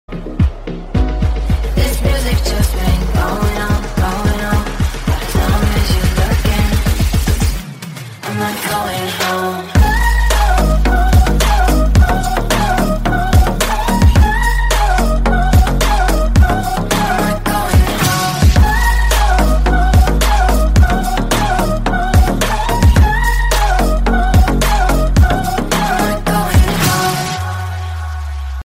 # Клубные Рингтоны
# Танцевальные Рингтоны